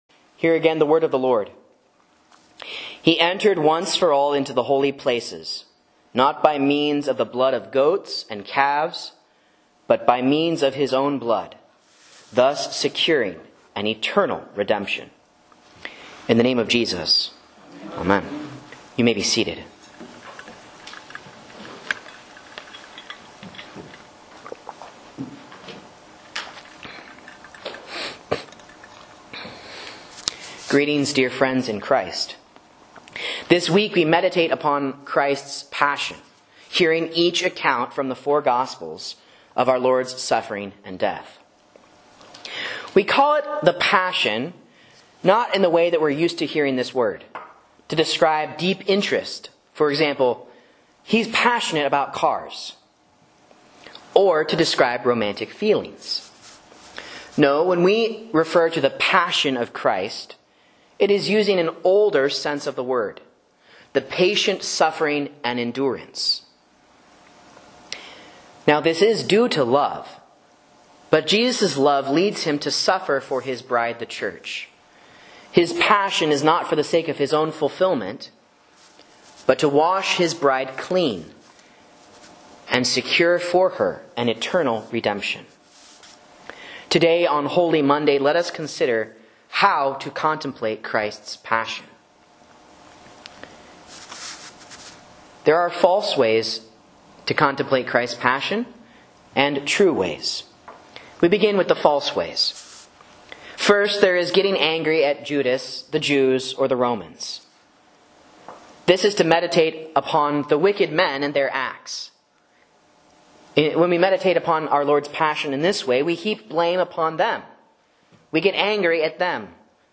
A Sermon on Hebrews 9:12 & St. Matthew's Passion for Holy Monday